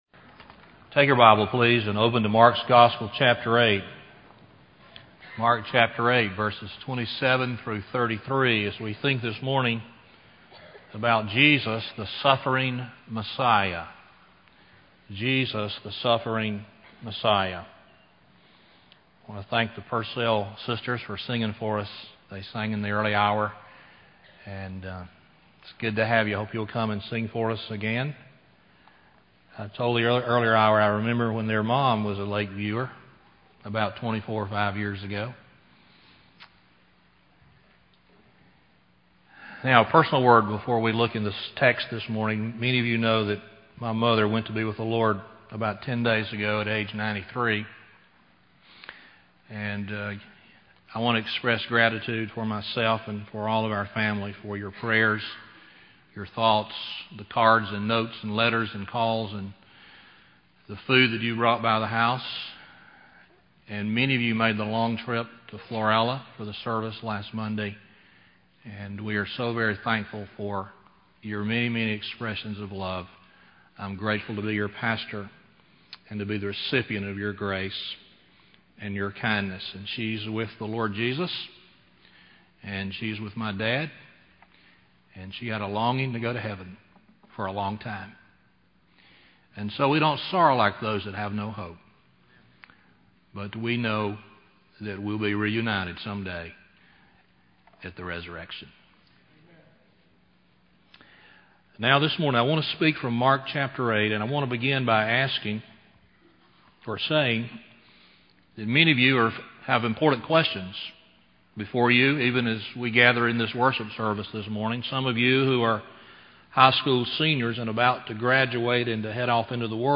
Lakeview Baptist Church - Auburn, Alabama